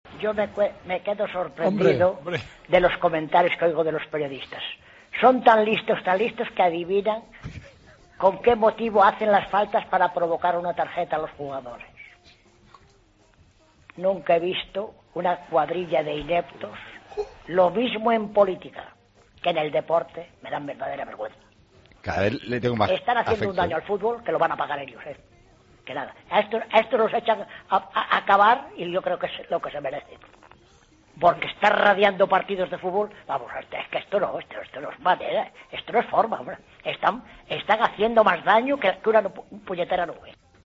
El oyente enfurecido